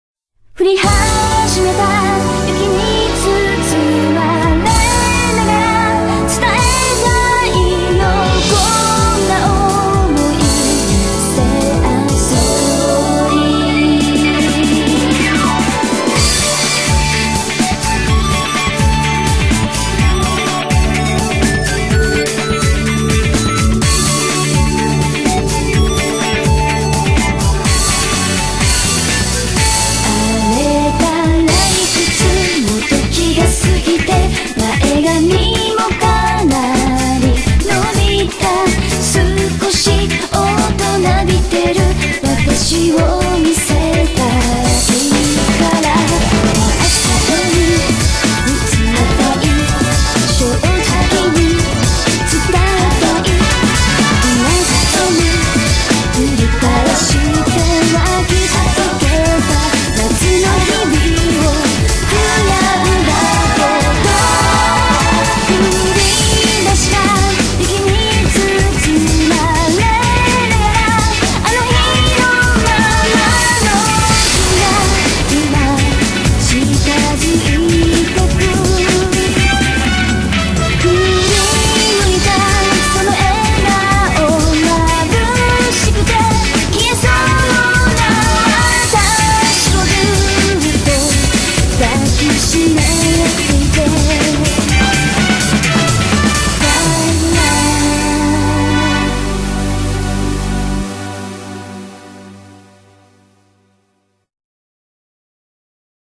BPM125
Audio QualityPerfect (High Quality)
Comments[WINTER POP]